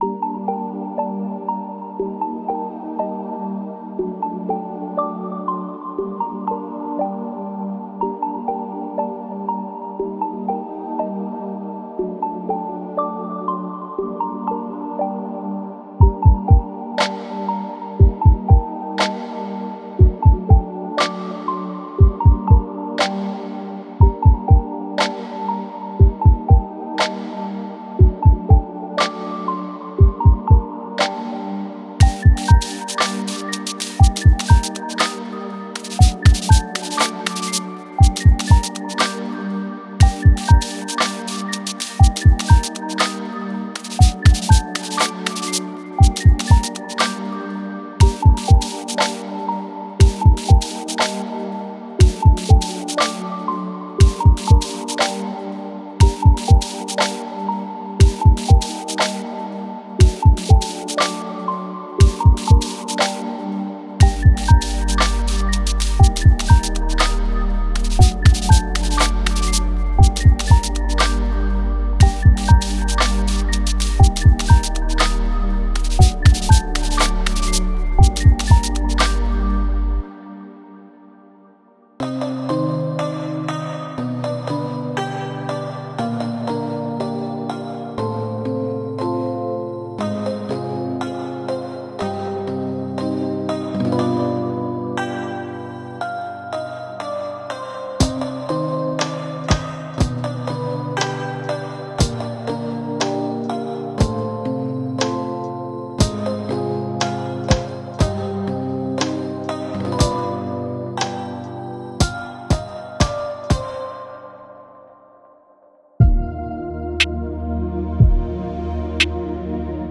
Ambient Chill Out / Lounge Cinematic / FX